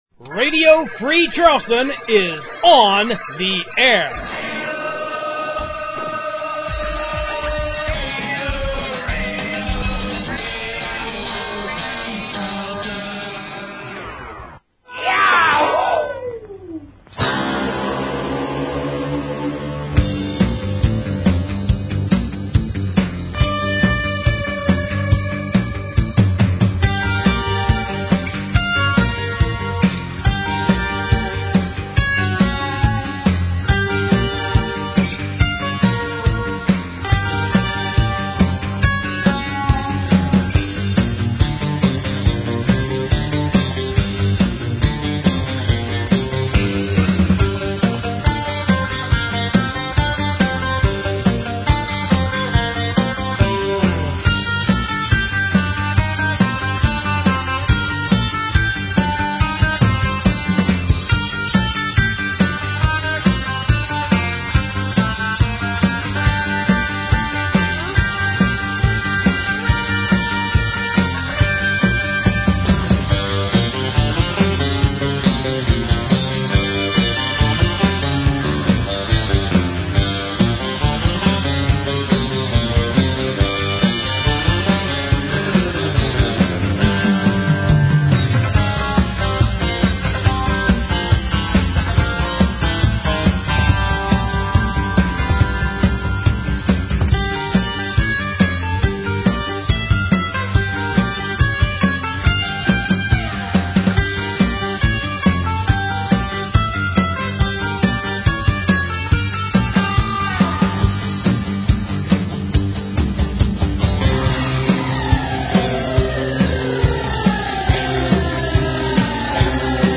relaxing, semi-ambient tunes